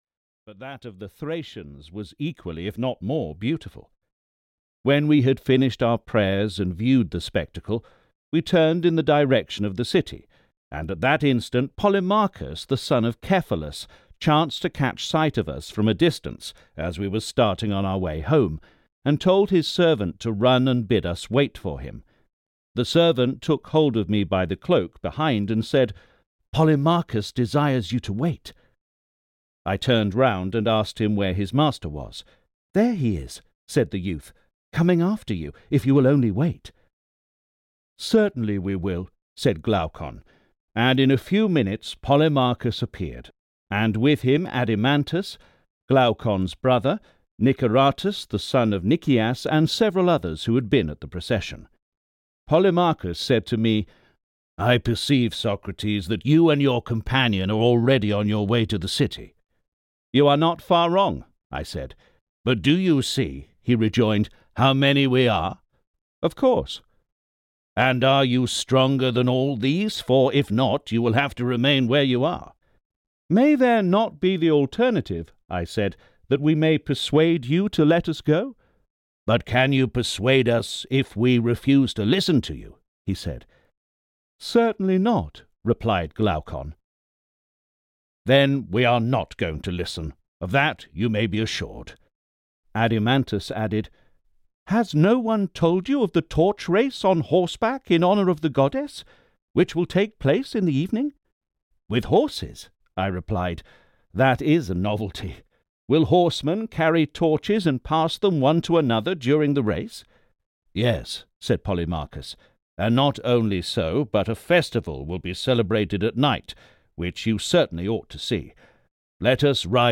The Republic (EN) audiokniha
Ukázka z knihy